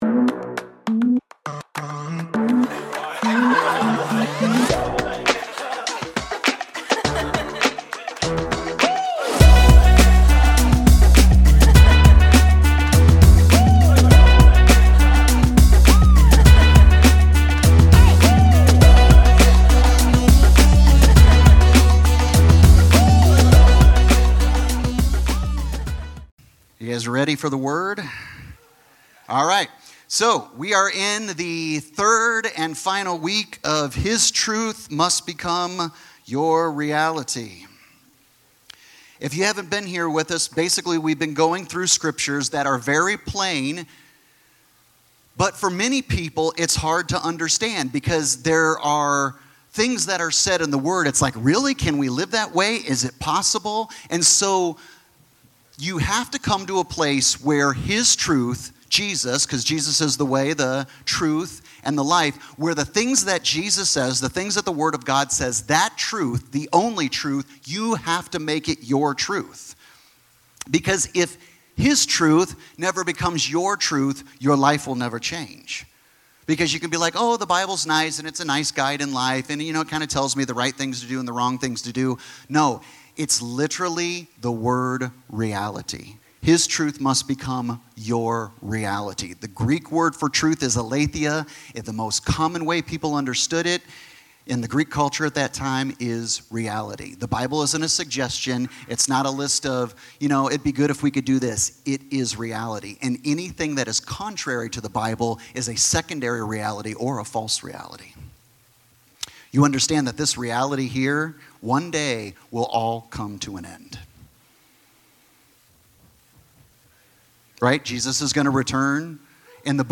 Sermons | 7 Mountains Church